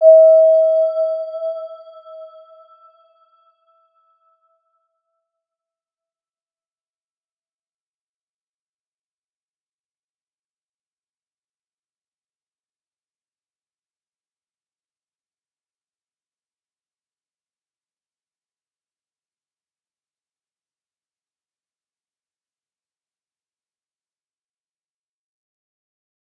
Round-Bell-E5-mf.wav